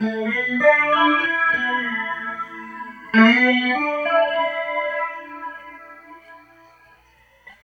43 GUIT 4 -L.wav